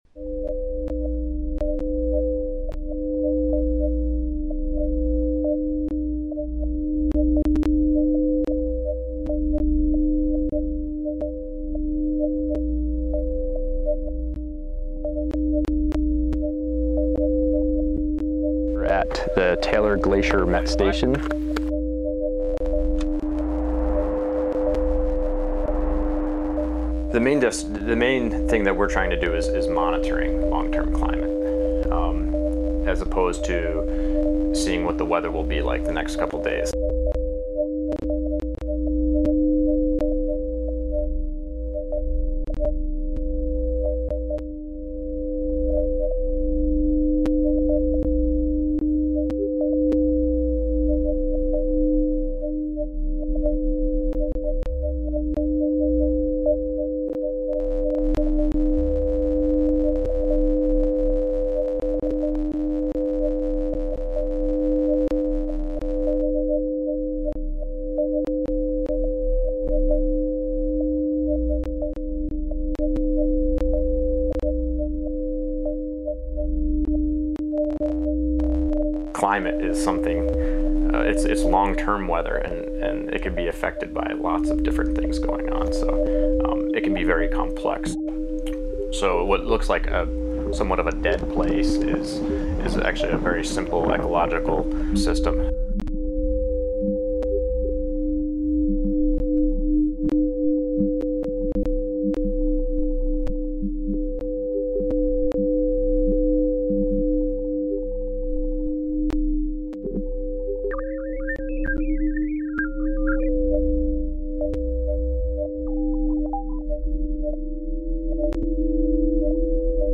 Listen to the sounds of Antarctica’s Taylor Glacier Above